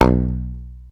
33. 33. Percussive FX 32 ZG